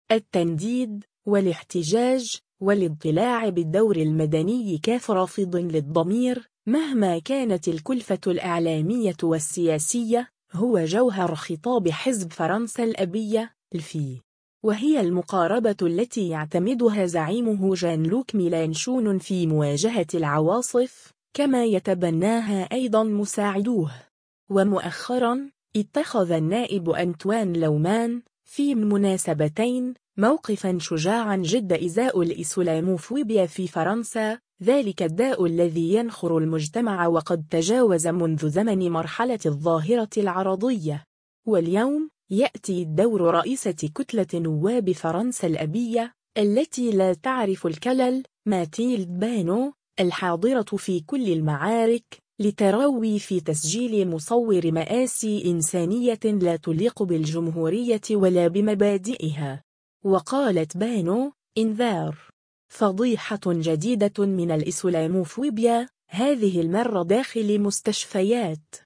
و اليوم، يأتي دور رئيسة كتلة نواب «فرنسا الأبية»، التي لا تعرف الكلل، ماتيلد بانو، الحاضرة في كل المعارك، لتروي في تسجيل مصوّر مآسي إنسانية لا تليق بالجمهورية ولا بمبادئها.